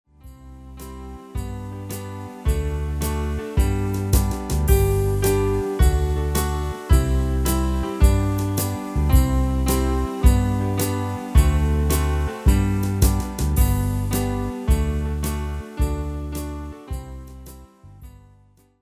slovenské koledy v ľahkej úprave pre klavír